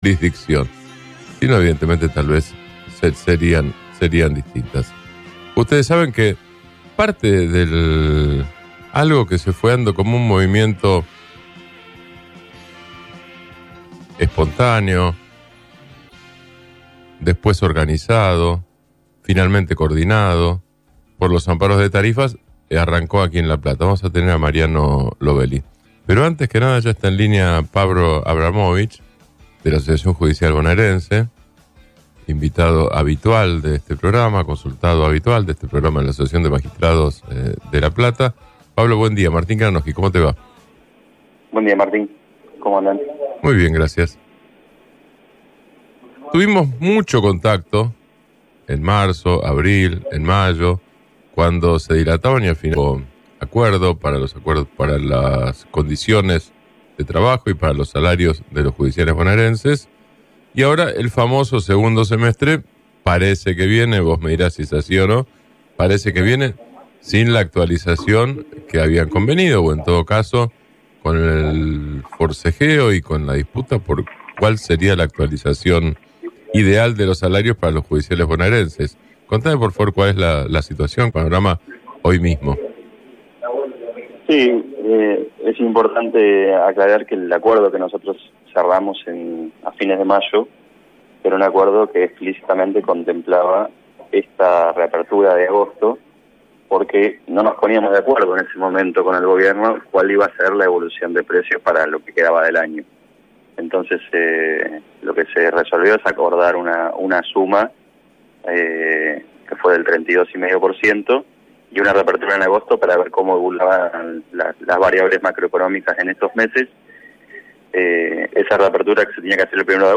En diálogo con JUSTICIA EN PRIMERA, el ciclo radial que se emite todos los jueves por LA REDONDA 100.3